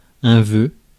Ääntäminen
Ääntäminen US Haettu sana löytyi näillä lähdekielillä: englanti Käännös Konteksti Ääninäyte Substantiivit 1. civilité {f} 2. vœu {m} monikossa France Compliments on sanan compliment monikko.